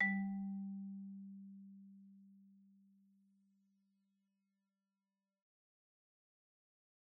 Marimba_hit_Outrigger_G2_loud_01.wav